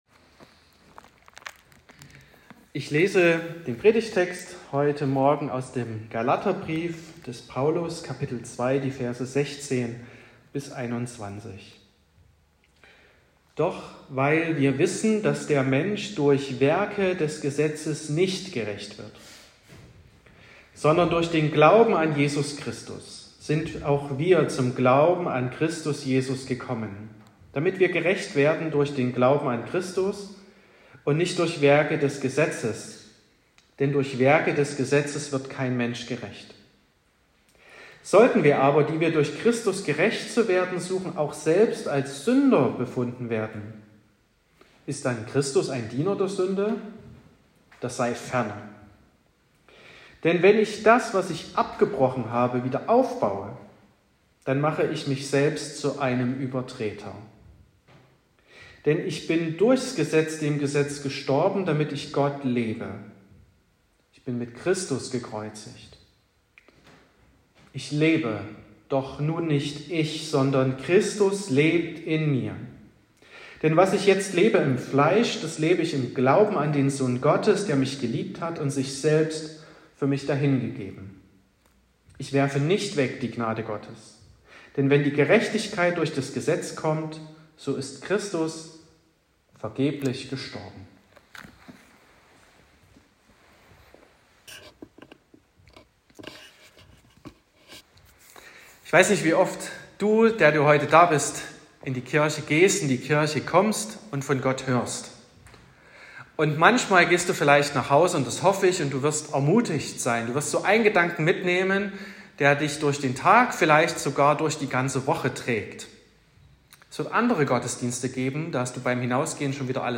11.08.2024 – Gottesdienst
Predigt und Aufzeichnungen